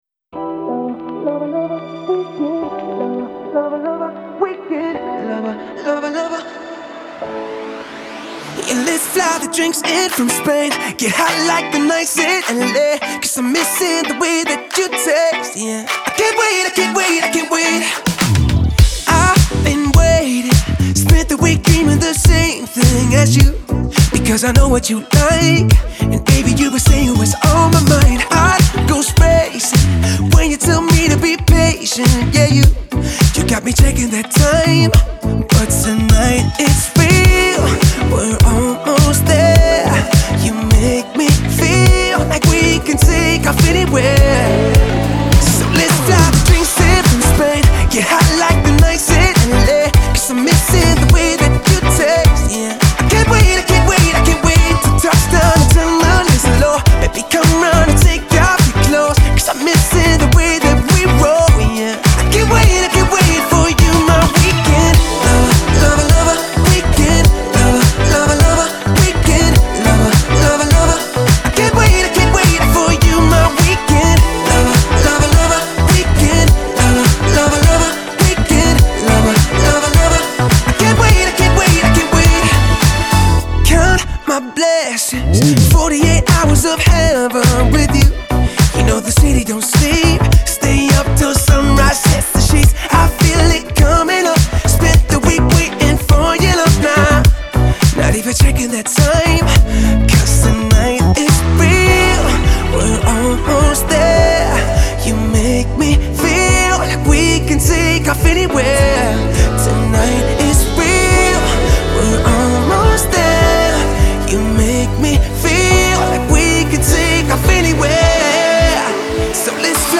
Genre : Electro, Alternative